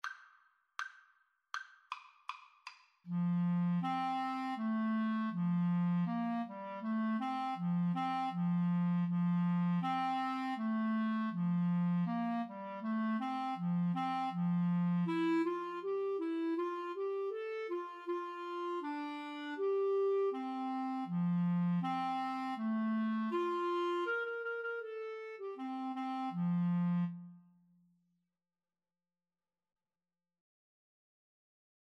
Very Fast =80
Clarinet Duet  (View more Easy Clarinet Duet Music)